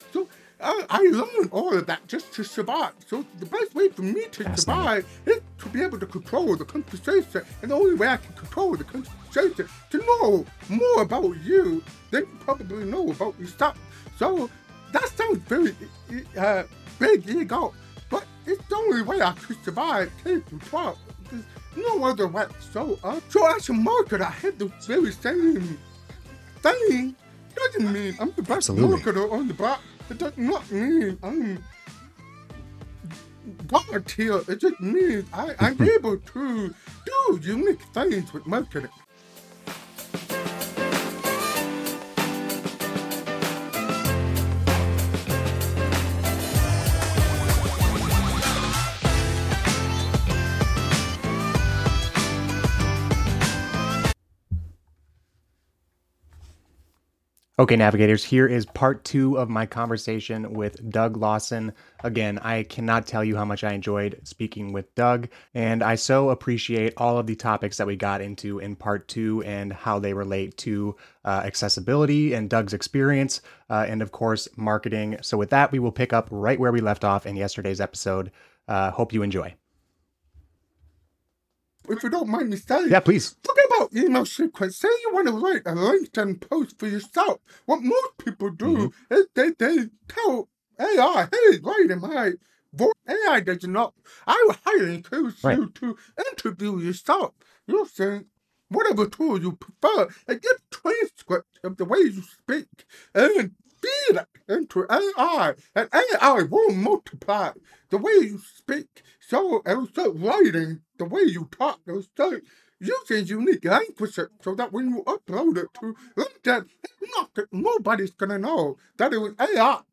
In this second part of our interview